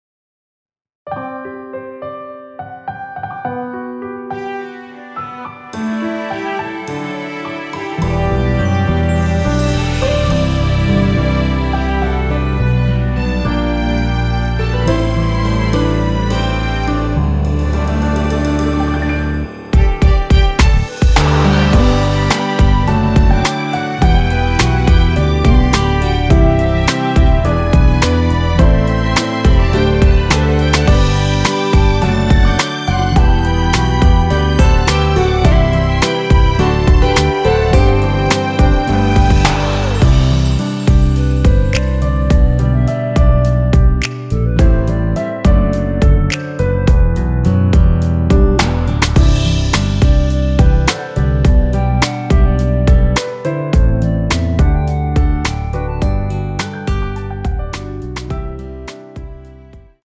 원키에서(-1)내린 멜로디 포함된 MR입니다.(미리듣기 확인)
앨범 | O.S.T
앞부분30초, 뒷부분30초씩 편집해서 올려 드리고 있습니다.
중간에 음이 끈어지고 다시 나오는 이유는